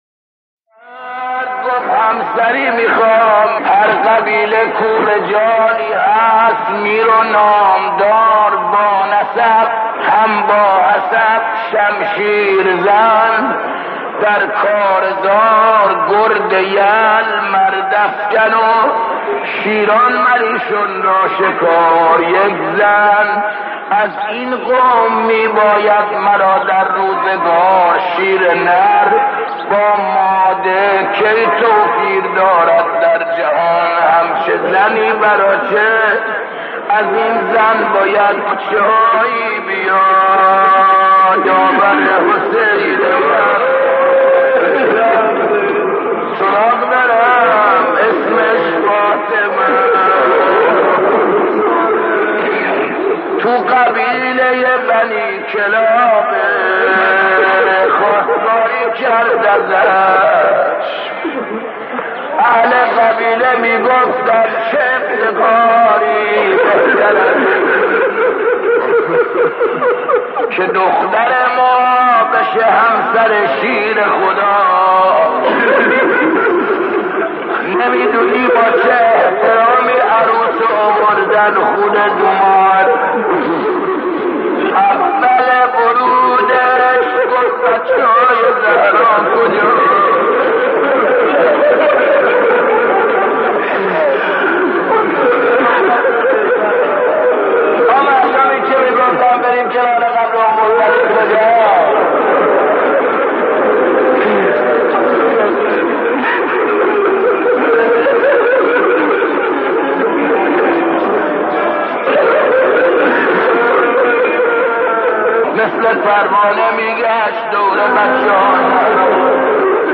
در پرده عشاق، صدای مداحان و مرثیه‌خوانان گذشته تهران قدیم را خواهید شنید که صدا و نفسشان شایسته ارتباط دادن مُحب و مَحبوب بوده است.